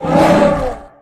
kaiju_bite_01.ogg